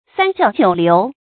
成語注音ㄙㄢ ㄐㄧㄠˋ ㄐㄧㄨˇ ㄌㄧㄨˊ
成語拼音sān jiào jiǔ liú
三教九流發音
成語正音教，不能讀作“jiāo”。